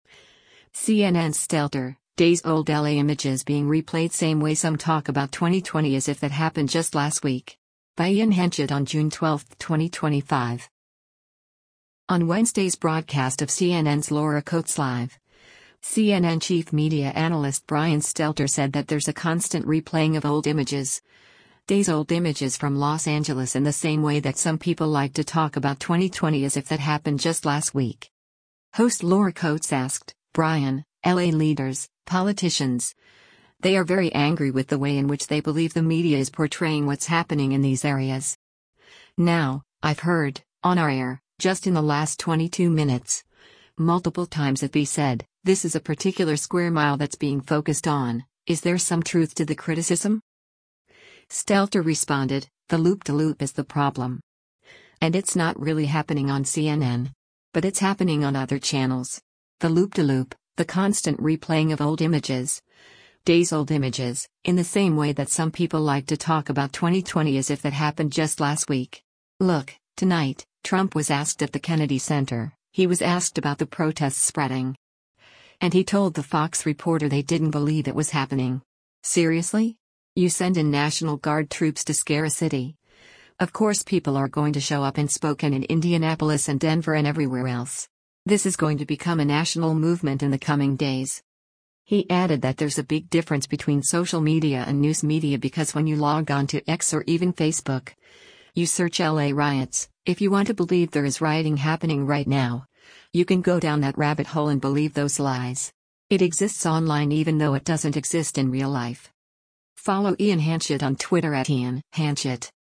On Wednesday’s broadcast of CNN’s “Laura Coates Live,” CNN Chief Media Analyst Brian Stelter said that there’s a “constant replaying of old images, days-old images” from Los Angeles “in the same way that some people like to talk about 2020 as if that happened just last week.”